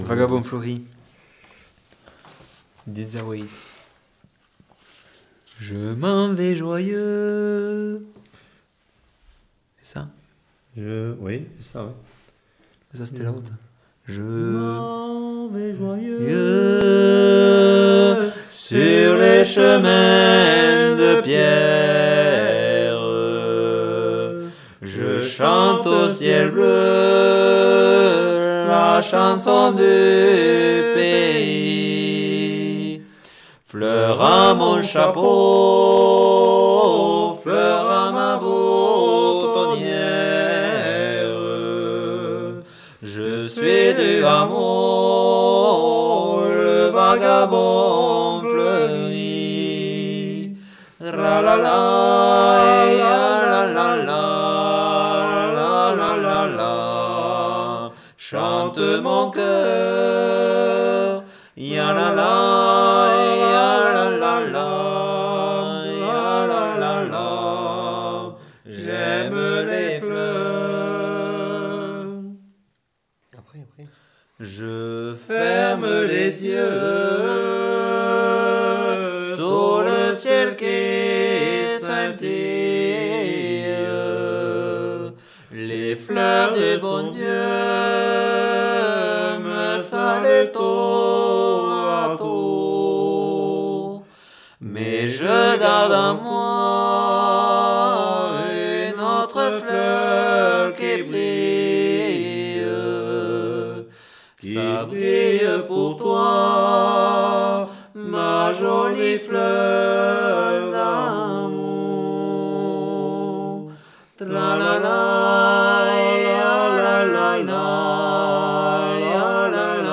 Aire culturelle : Bigorre
Lieu : Bénac
Genre : chant
Effectif : 2
Type de voix : voix d'homme
Production du son : chanté
Descripteurs : polyphonie